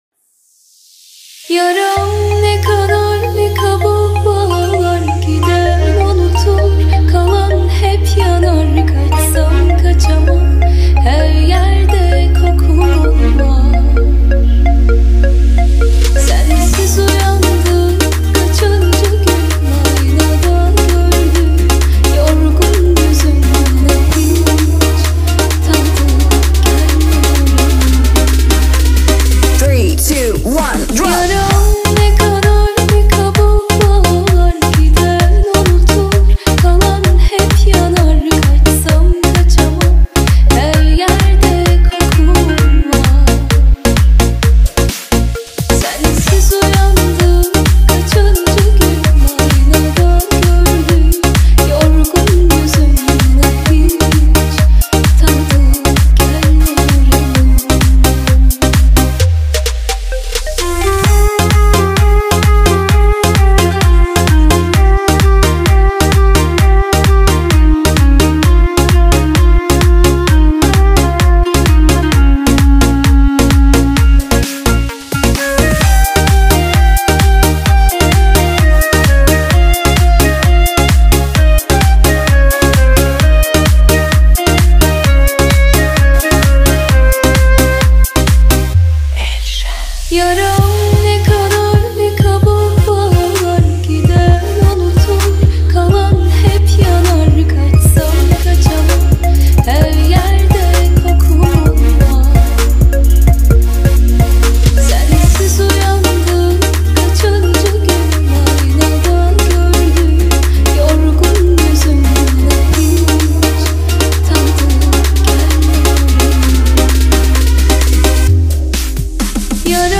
ریمیکس ترکیه ای